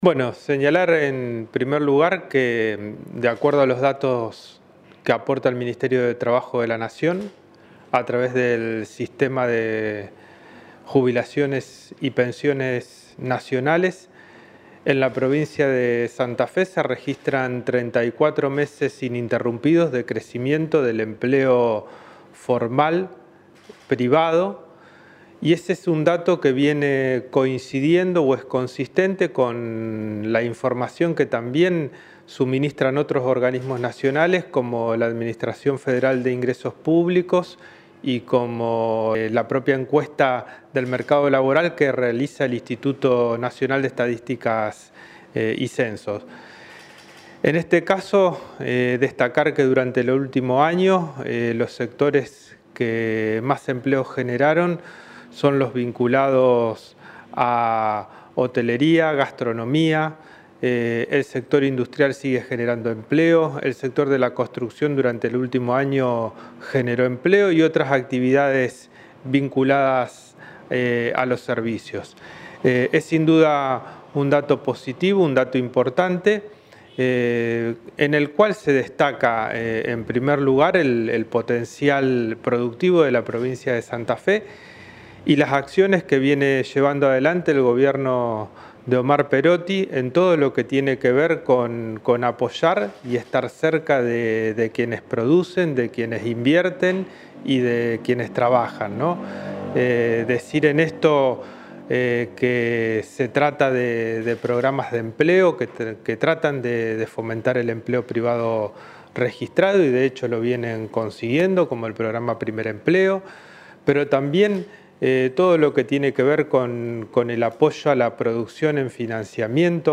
Declaraciones Pusineri